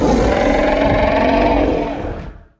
Vertacines_roar.ogg